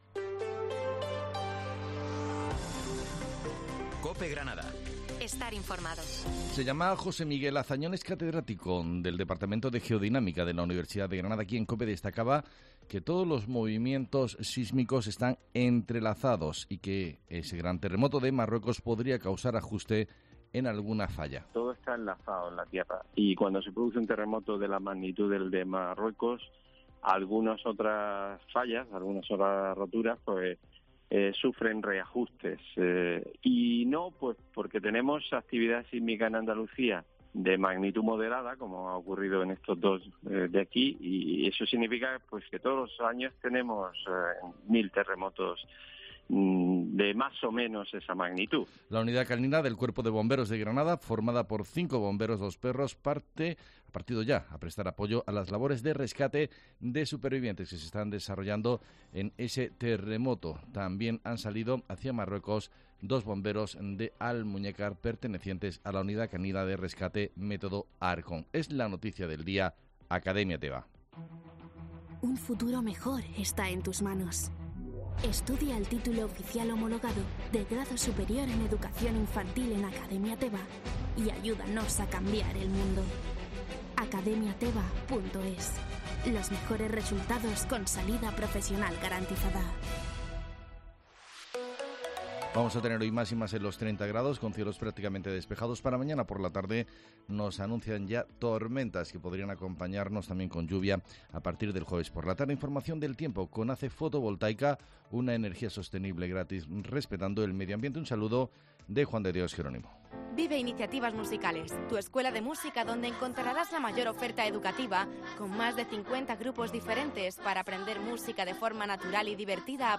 Herrera en Cope Granada, informativo 12 de septiembre